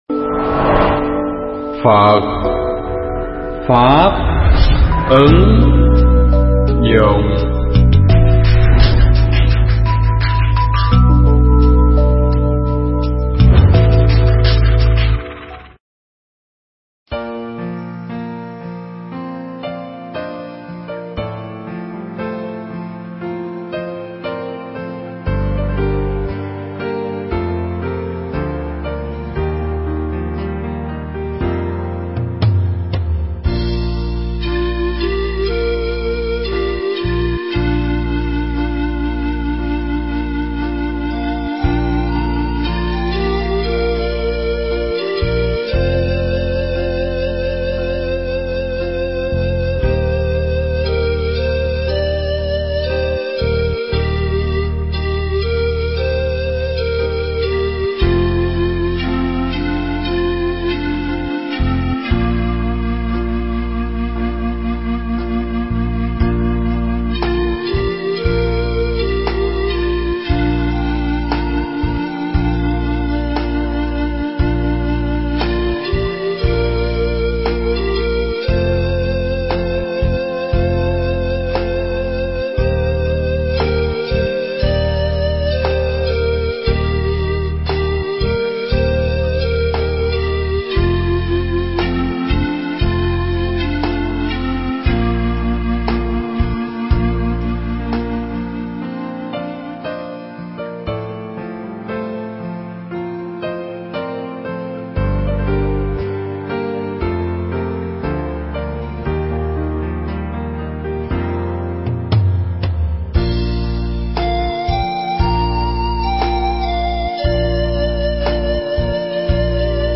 Thuyết pháp Tận Thế Do Đâu
trong khoá tu Một Ngày An Lạc lần thứ 18 tại tu viện Tường Vân